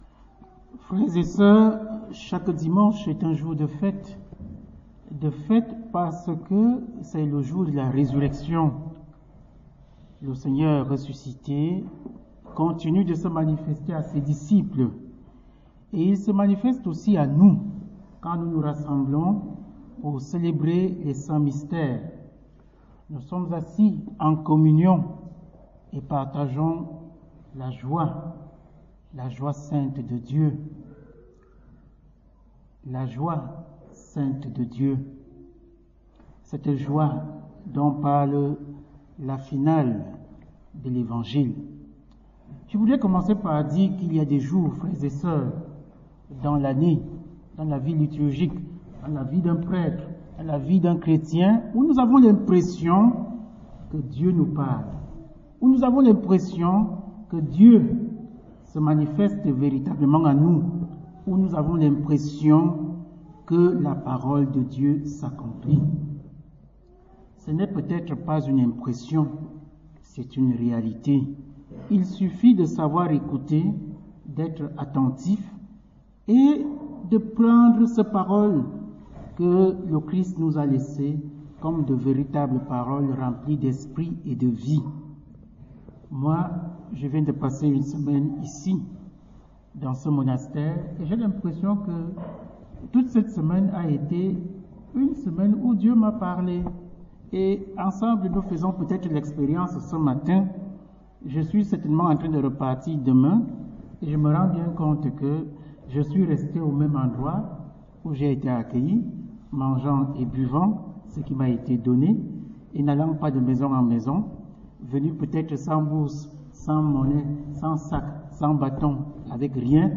Prédications 2021-2022